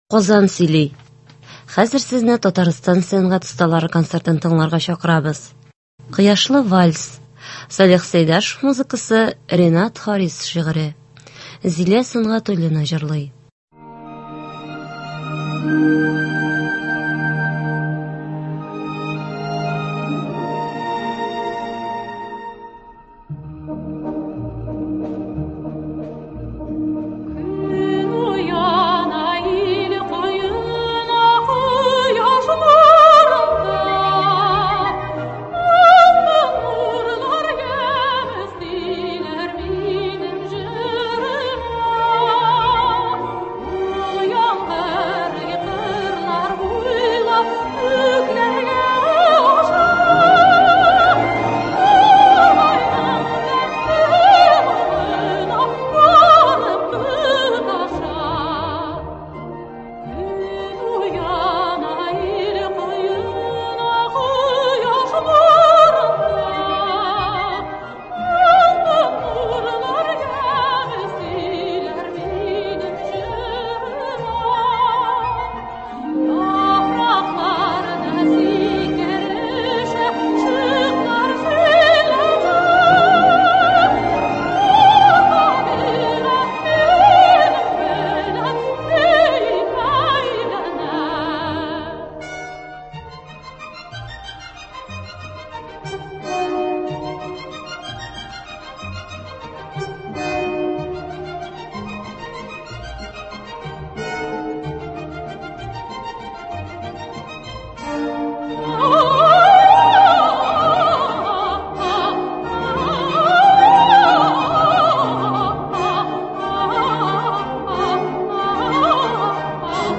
Көндезге концерт.
Татарстанның сәнгать осталары концерты.